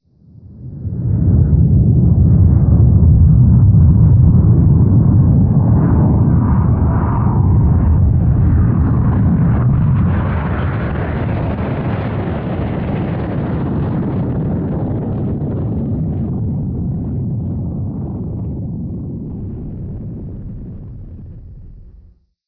spaceship_fadeout.wav